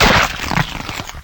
Splat Sound 6